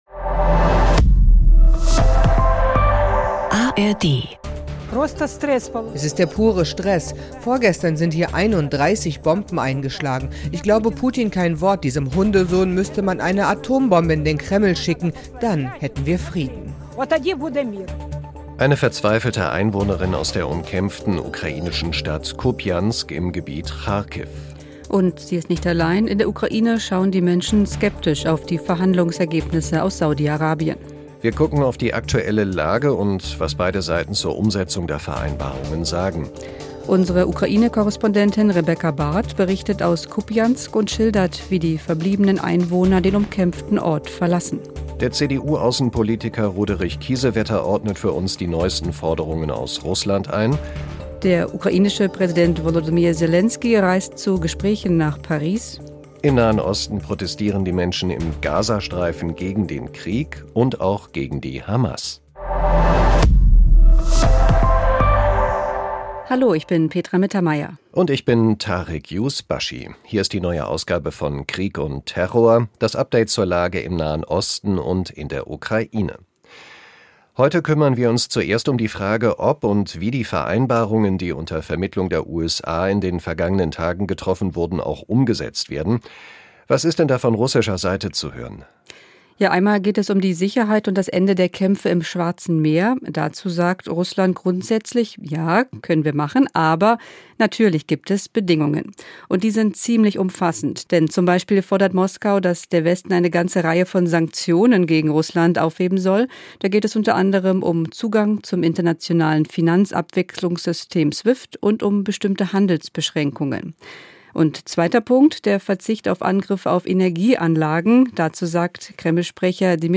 … continue reading 1585 つのエピソード # Nachrichten # NDR Info